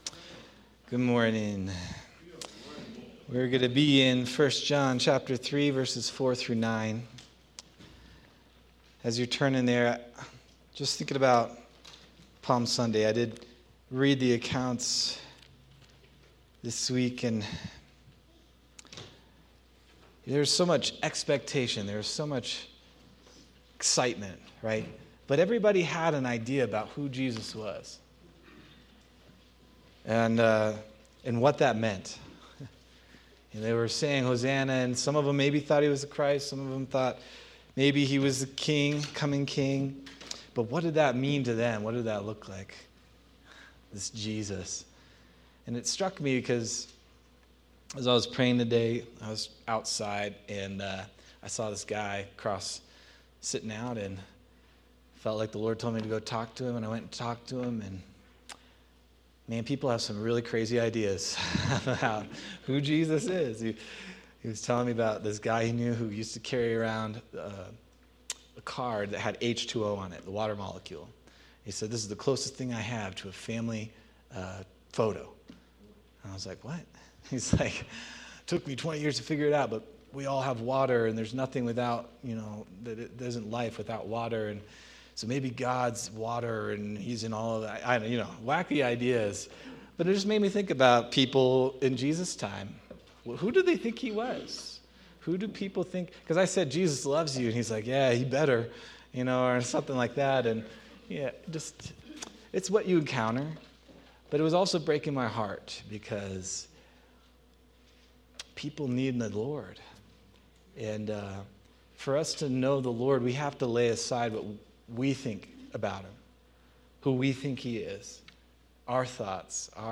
March 29th, 2026 Sermon